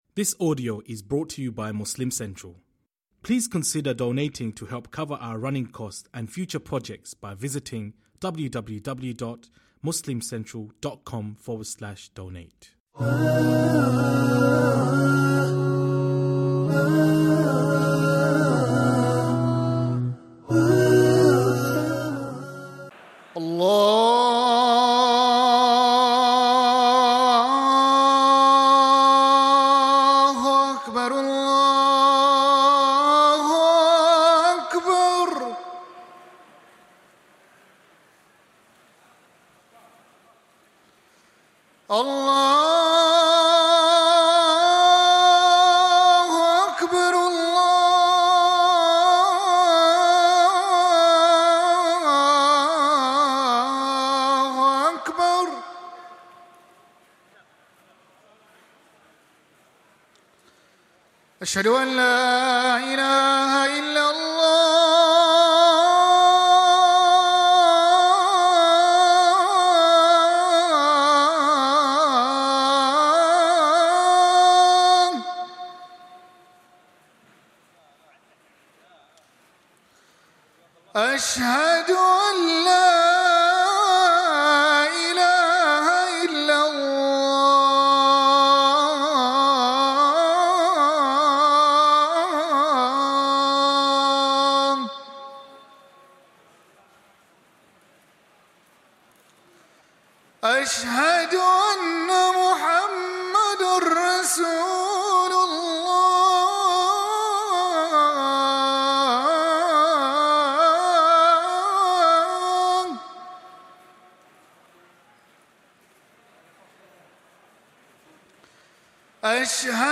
Azan / Ezan / Athan / Adhan • Audio Podcast